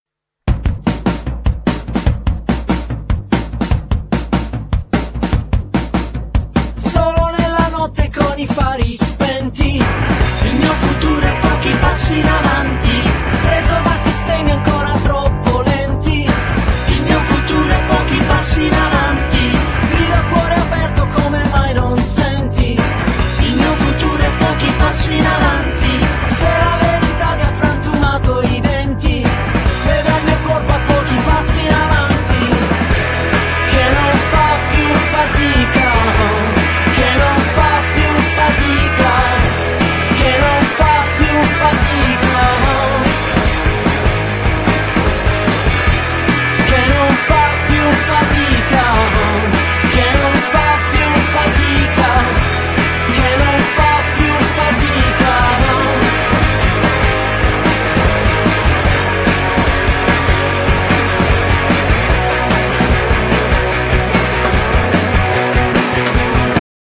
Genere : Pop / Rock